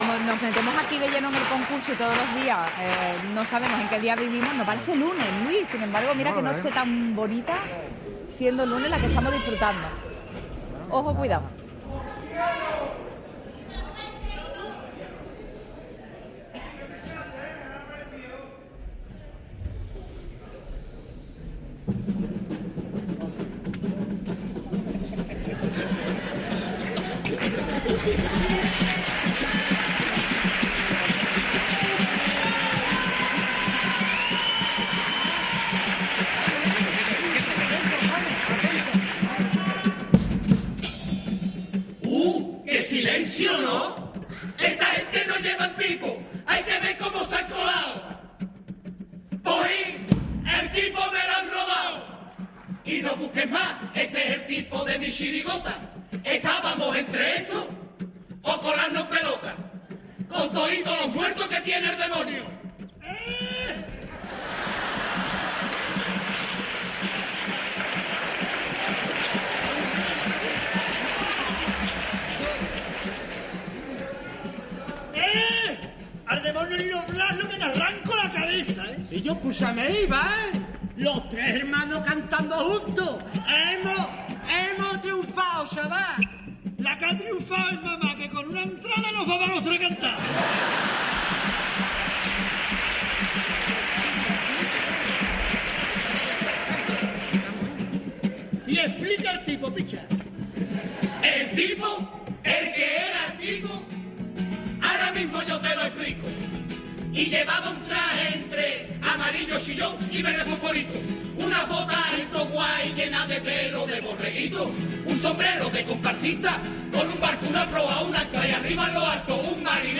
Concurso Oficial de Agrupaciones del Carnaval de Cádiz